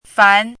chinese-voice - 汉字语音库
fan2.mp3